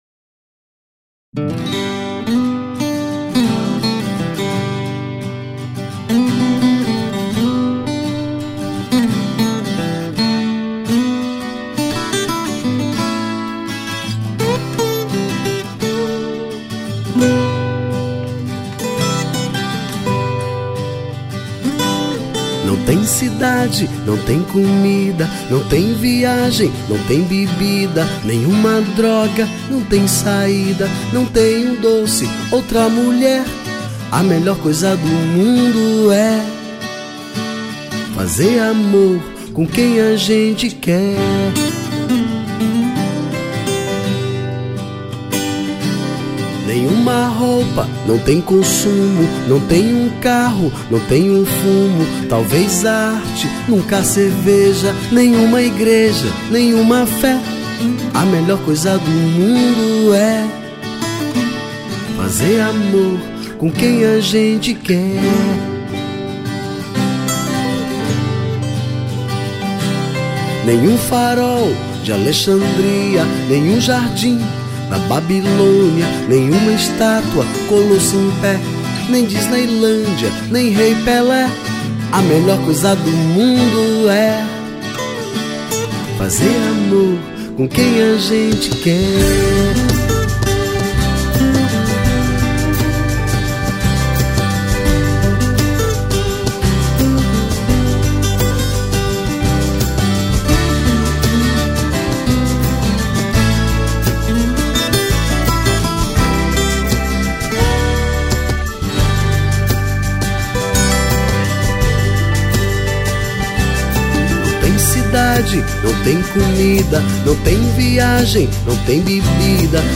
2959   03:53:00   Faixa: 2    Rock Nacional